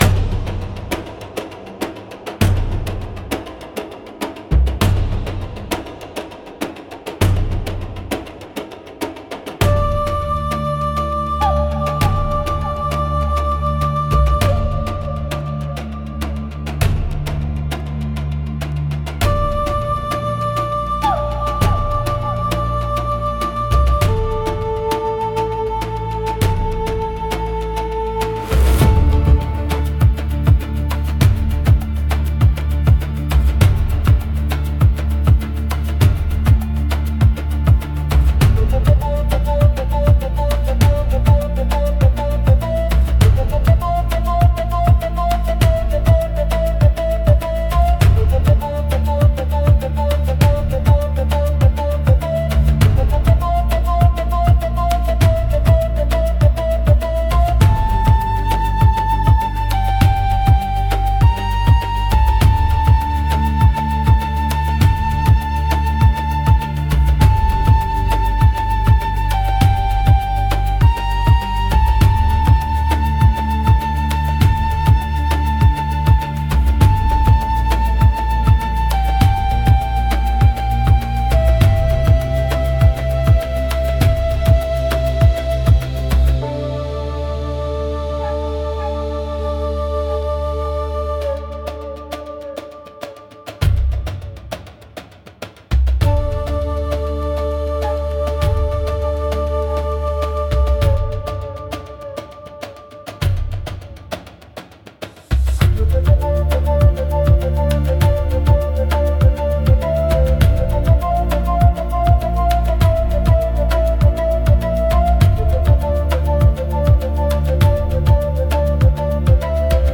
Instrumental / 歌なし
ミステリアスな、アンビエント・エレクトロニカ。
エキゾチックなメロディと民族的なパーカッションが、幻想的な世界観を紡ぎ出します。
内なる情熱が静かに目覚めるような、美しくも力強いサウンド。